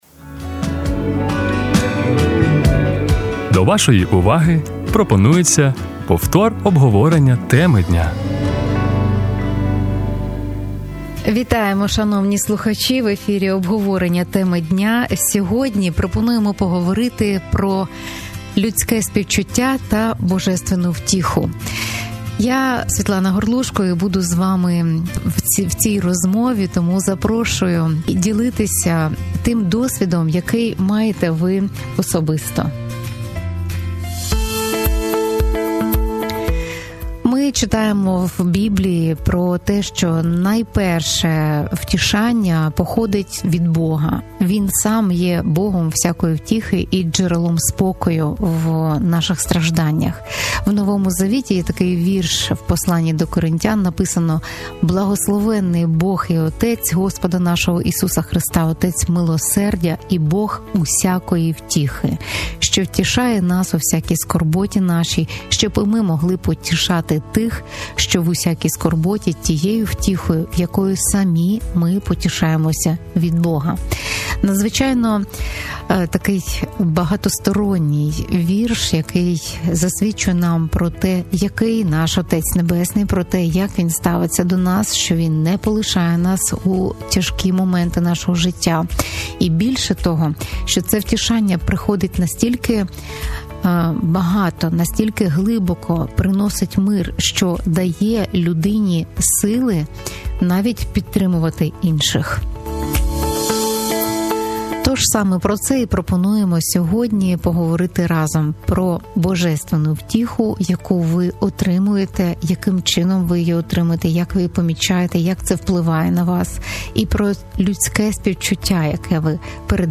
Що означає "Боже втішання" на практиці, як його прийняти і в який спосіб передавати іншим - про це почуєте в розмові зі слухачами.